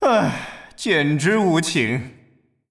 死亡语音